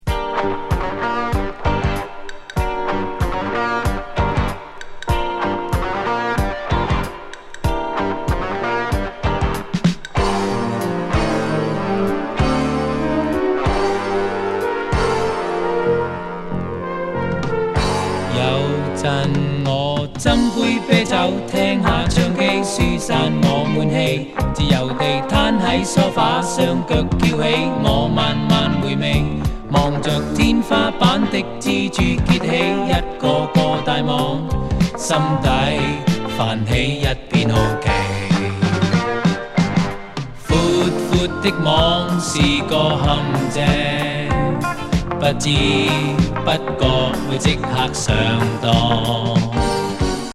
香港ミュージシャンによる和モノ・カバー企画盤。